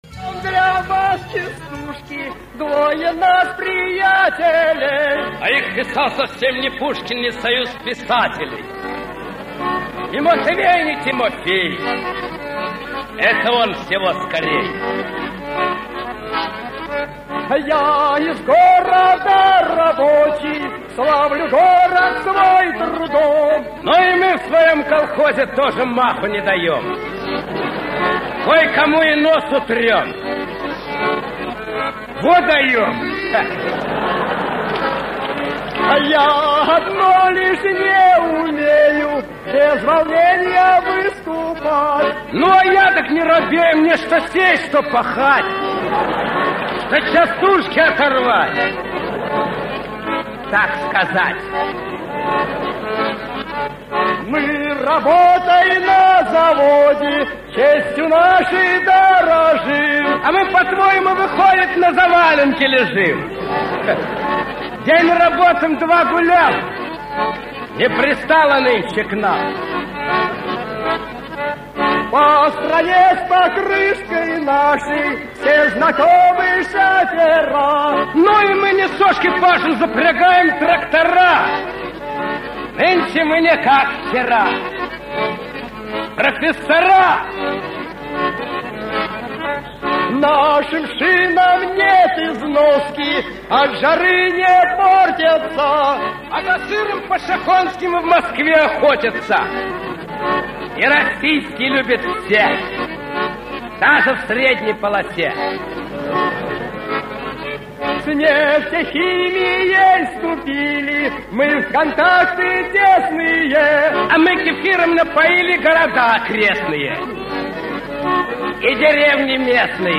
На опознание: = Частушки в исполнении куплетистов, запись с телеящика, времен тех застойных лет, предположительно с одного из "огоньков", подскажите уважаемые завальчане, кто эти двое исполнителей, может и кто знает какой год... или еще какая либо информация.
частушки запись с ОГОНЬКА - не знаю исполнителей и года.mp3